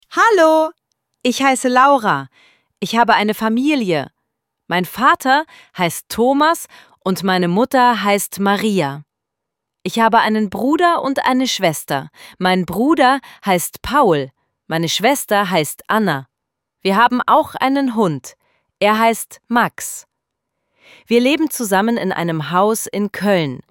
Izgovor – PRIČA:
ElevenLabs_Text_to_Speech_audio-39.mp3